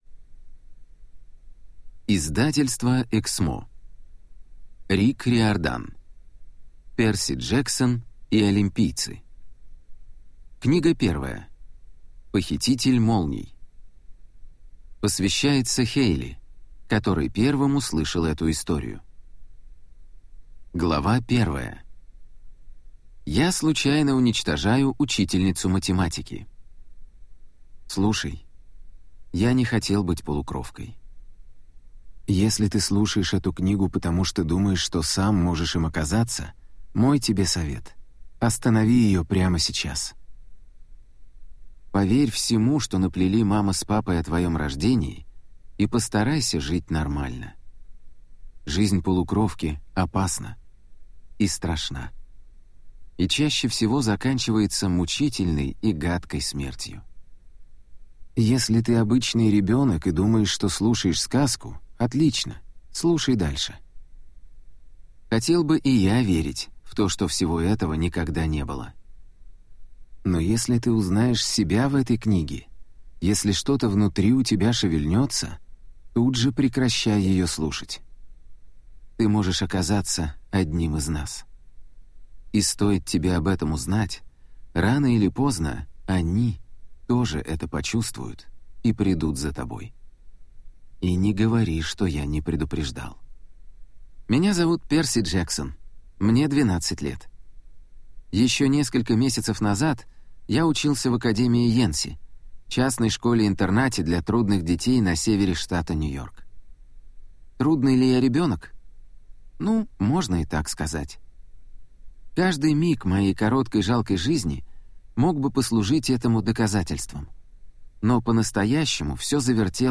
Аудиокнига Перси Джексон и похититель молний | Библиотека аудиокниг